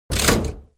Lever.wav